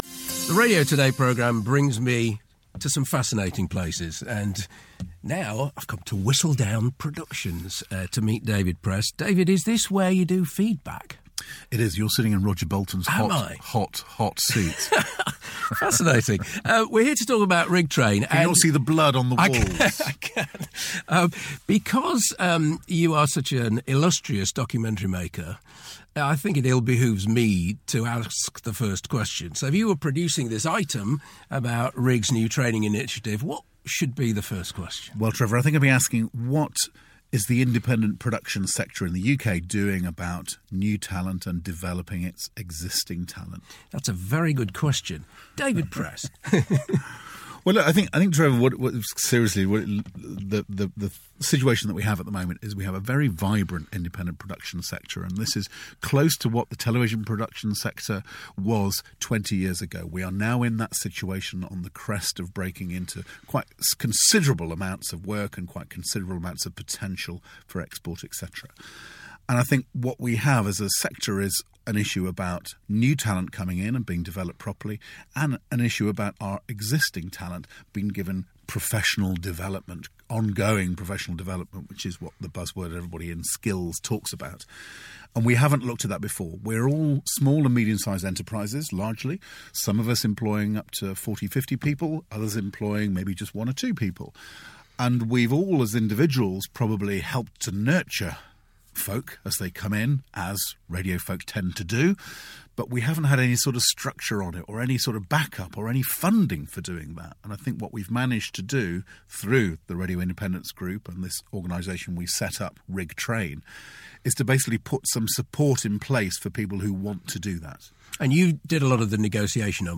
Radio Today interview